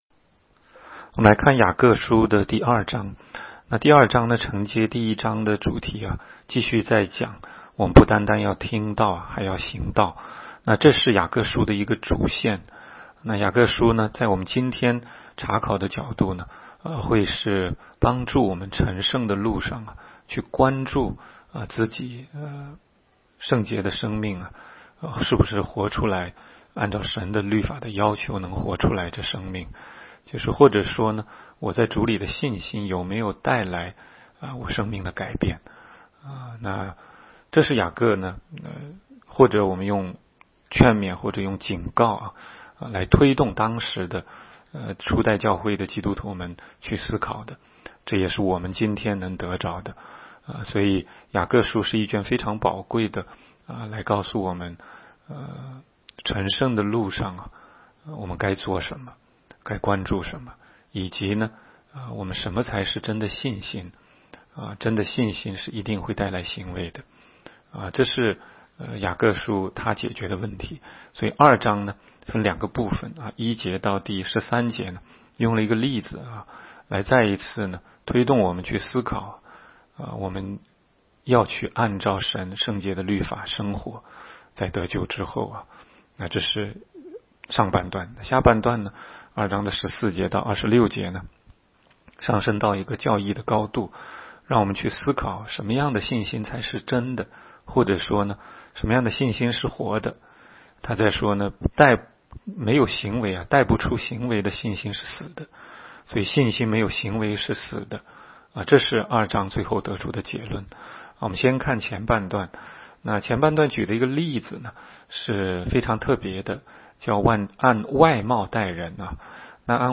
16街讲道录音 - 每日读经-《雅各书》2章
每日读经